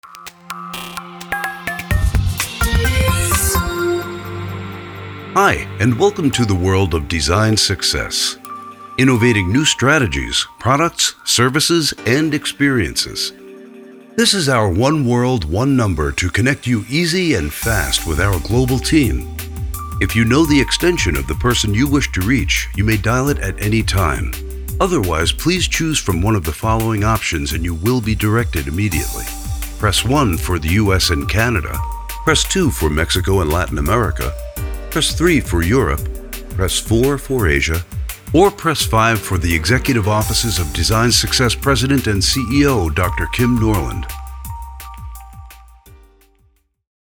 mid-atlantic
Sprechprobe: Sonstiges (Muttersprache):
Naturally deep, rich, smoky and soothing, but extremely diverse!